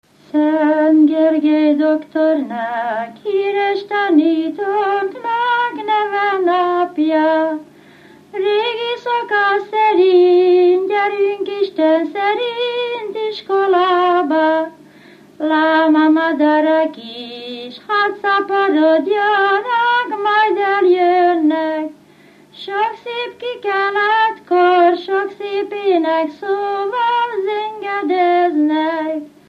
Felföld - Nógrád vm. - Karancskeszi
Műfaj: Gergelyjárás
Stílus: 7. Régies kisambitusú dallamok